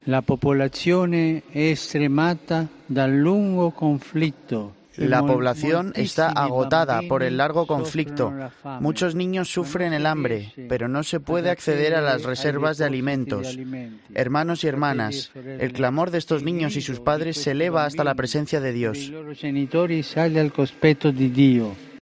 El grito de estos niños y sus padres se eleva ante Dios", dijo Francisco, momentos después del rezo del Ángelus.